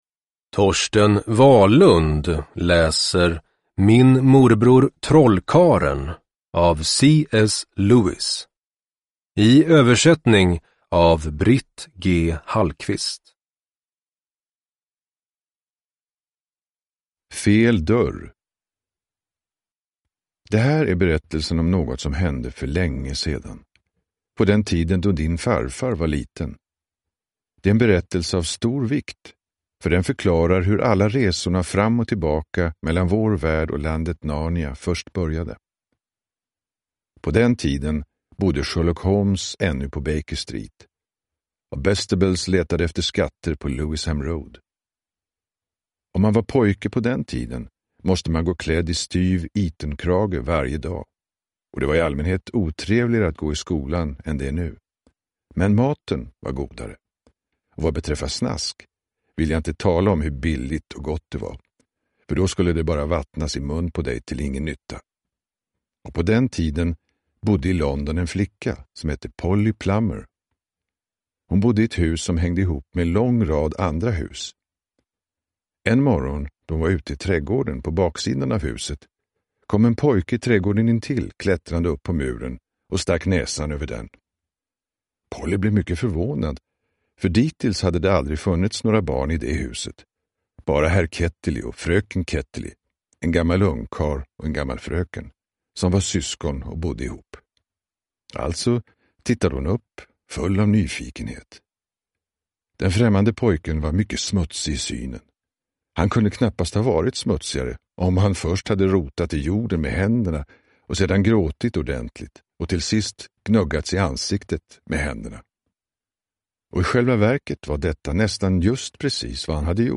Min morbror trollkarlen – Ljudbok – Laddas ner
Uppläsare: Torsten Wahlund